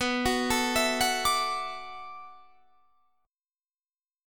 Listen to Bm11 strummed